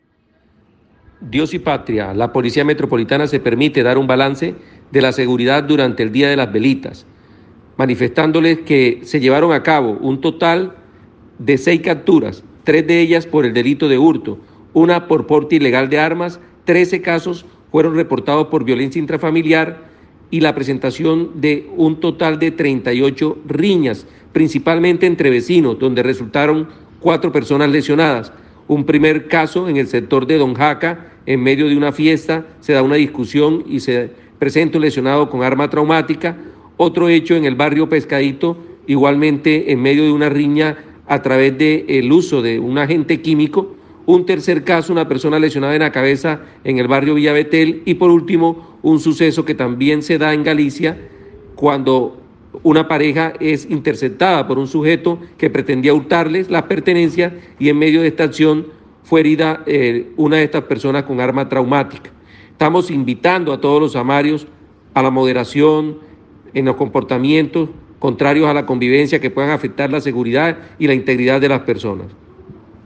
(AUDIODECLARACIONES) POLICIA METROPOLITANA DE SANTA MARTA ENTREGA BALANCE DE SEGURIDAD - LA GACETA CIÉNAGA ON LINE